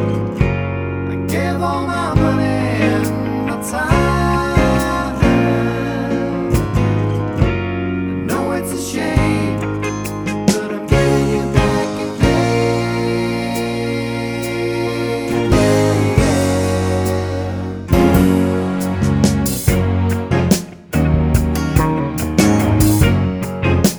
Soul / Motown